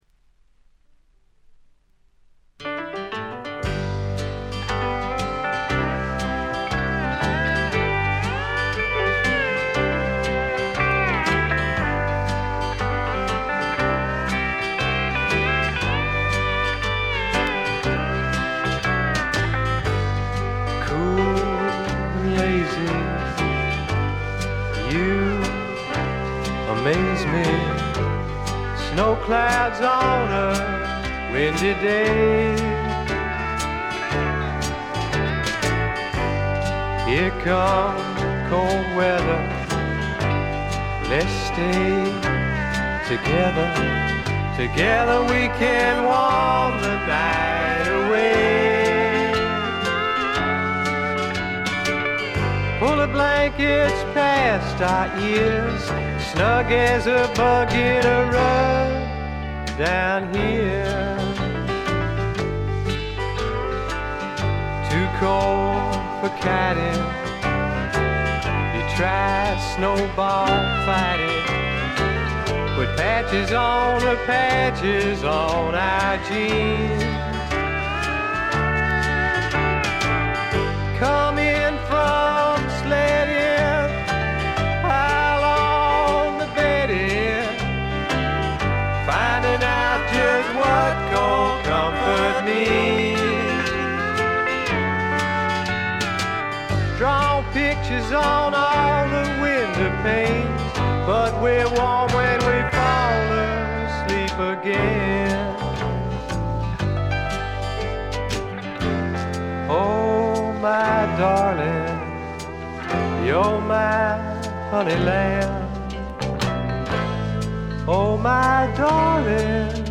ジャケットはまるで激渋の英国フォークみたいですが、中身はパブロック／英国スワンプの裏名盤であります。
カントリー風味、オールド・ロックンロールを元にスワンプというには軽い、まさに小粋なパブロックを展開しています。
試聴曲は現品からの取り込み音源です。